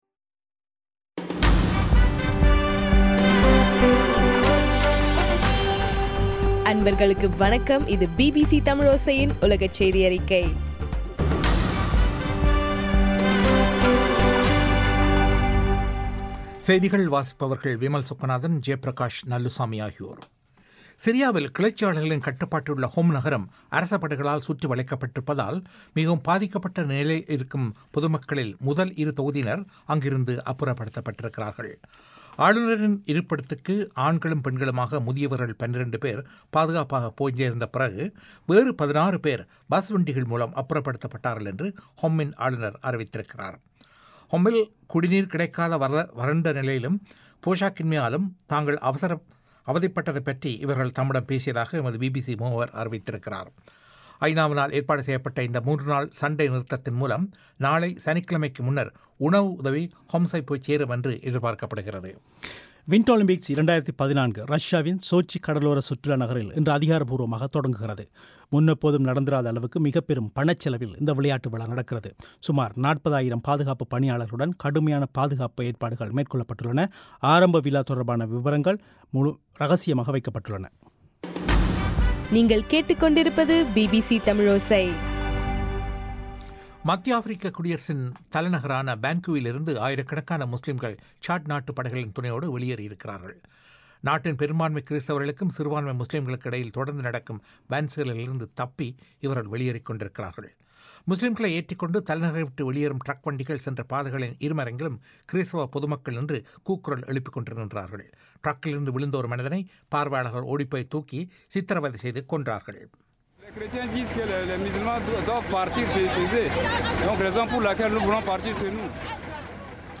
பிப்ரவரி 7, 2014 பிபிசி தமிழோசையின் உலகச் செய்திகள்